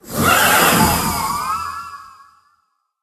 Cri de Sylveroy, le Cavalier d'Effroi dans Pokémon HOME.
Cri_0898_Cavalier_d'Effroi_HOME.ogg